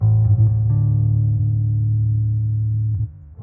描述：电贝司
Tag: 低音 电动